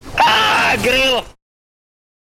aaaaa-grill_LoQmcxG.mp3